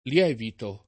lievito [ l L$ vito ] s. m.